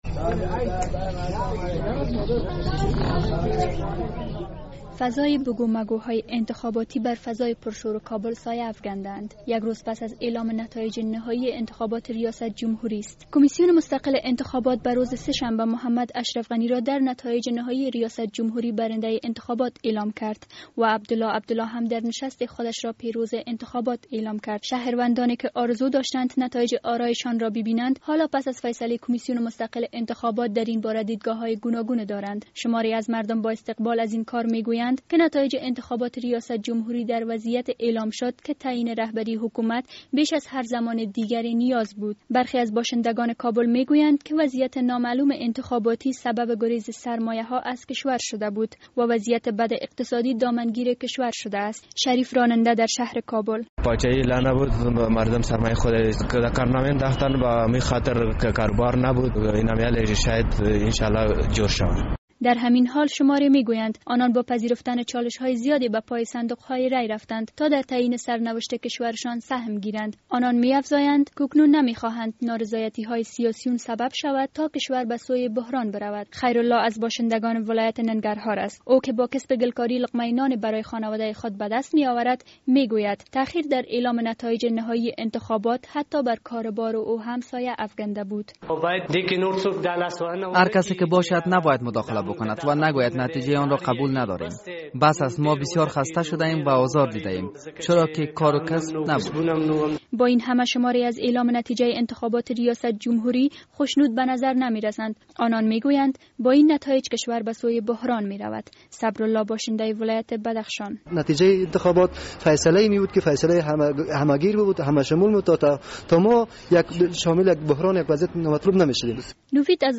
دیدگاه مختلف شهروندان افغانستان در مورد نتیجه نهایی انتخابات